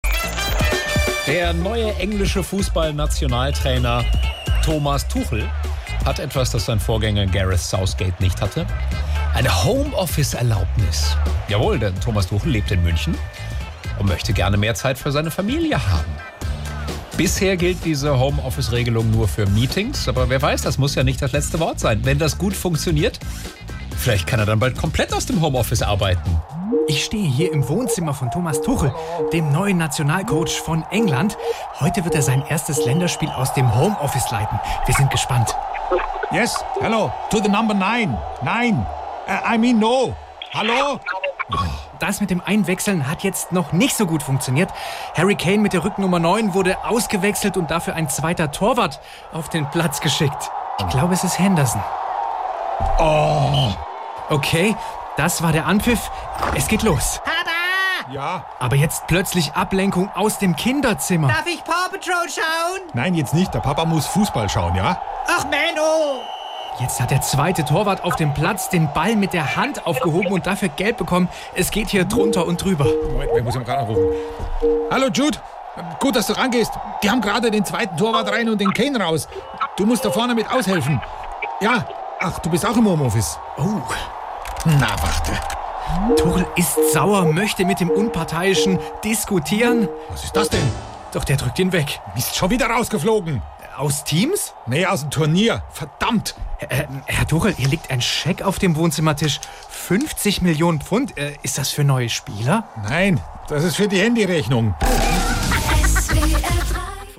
SWR3 Comedy Tuchel im Home-Office